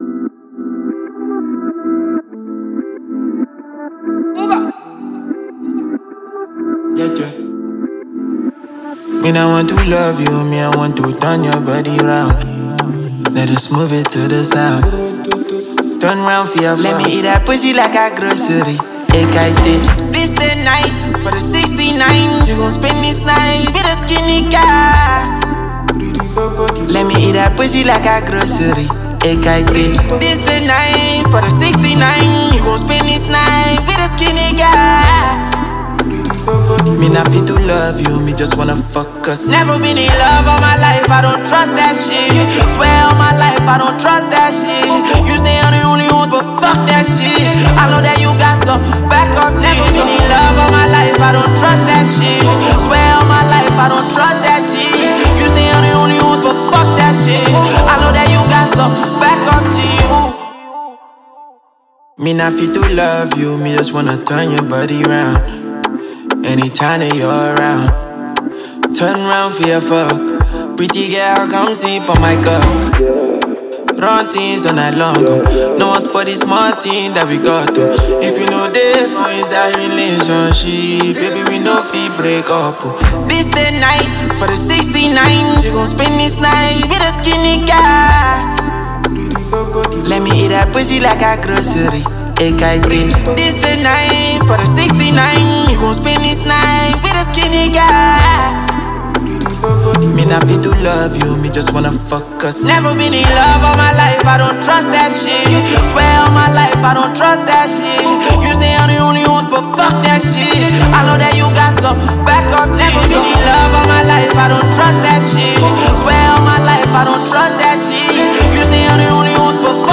rapper and singer